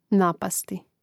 nàpasti napasti